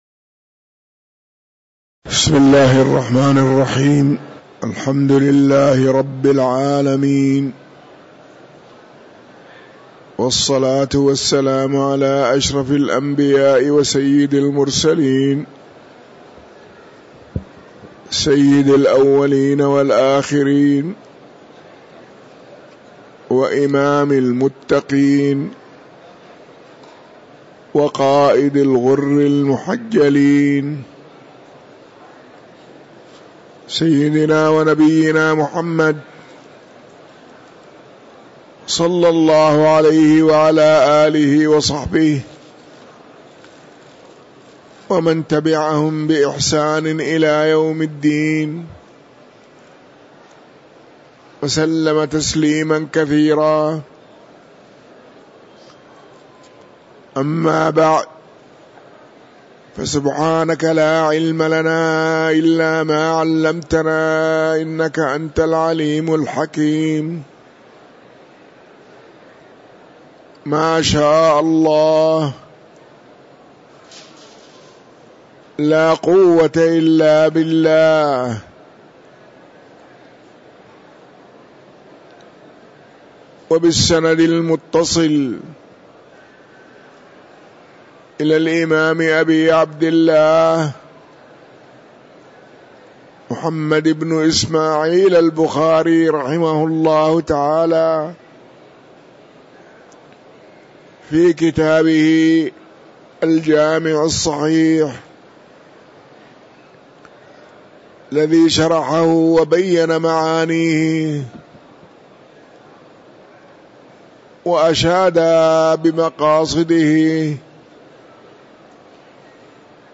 تاريخ النشر ٢٨ محرم ١٤٤٥ هـ المكان: المسجد النبوي الشيخ